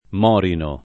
[ m 0 rino ]